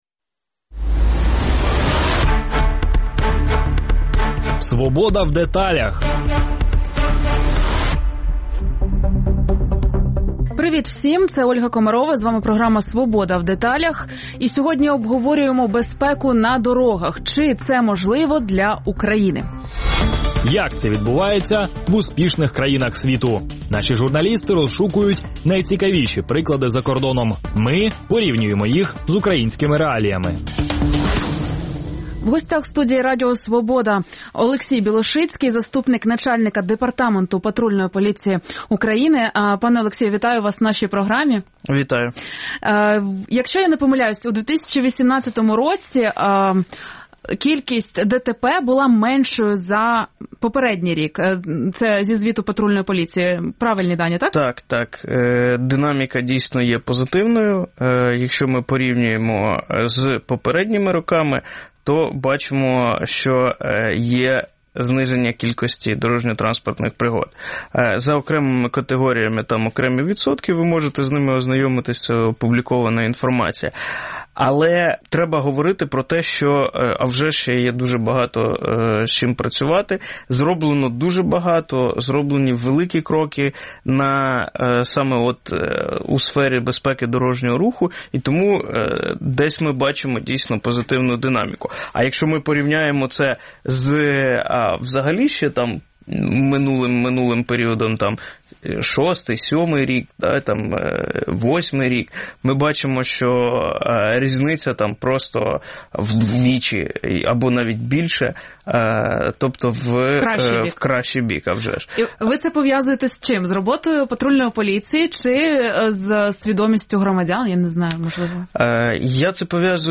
І чому водії в Україні не бояться порушувати правила дорожнього руху? На ці запитання відповідає заступник начальник Департаменту патрульної поліції України Олексій Білошицький.